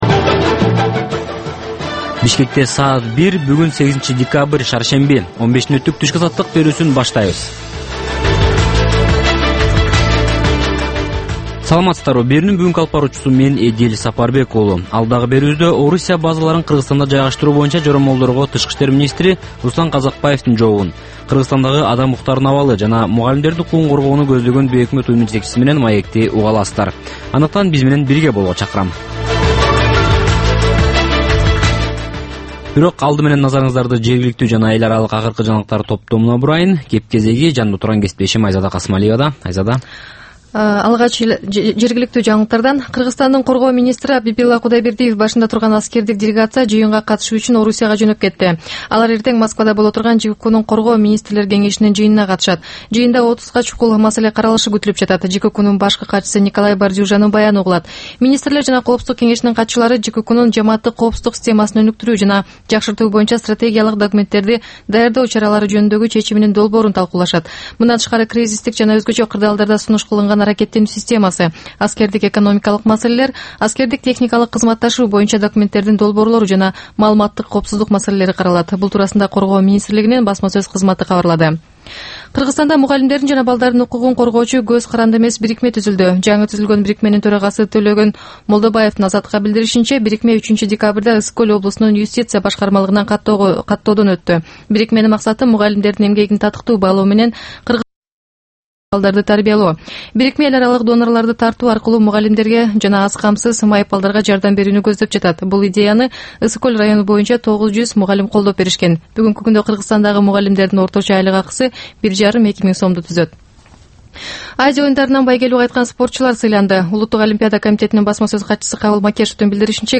Чак түштөгү кабарлар